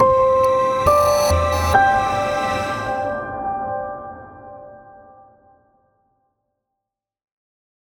audio trademark can help your brand or business stand out!